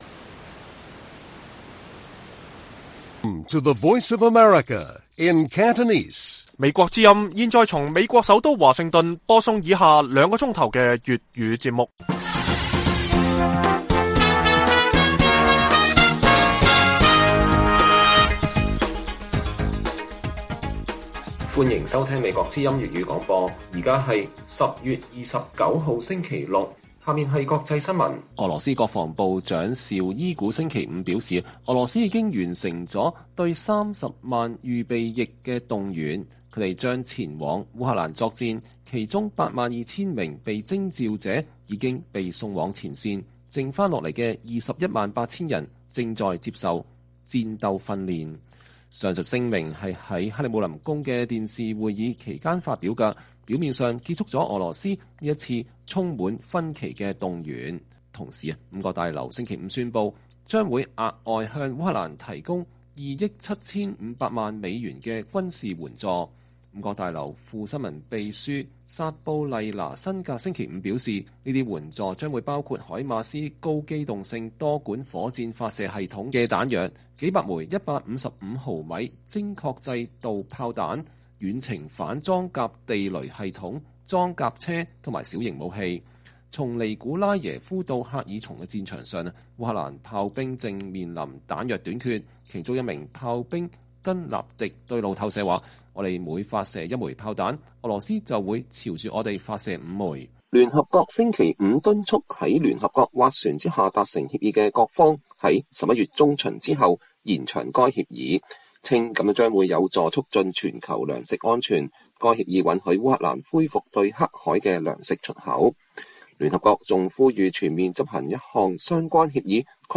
粵語新聞 晚上9-10點 : 中國駐英大使館回應毆打香港示威者事件 警告指包庇港獨為英帶來災難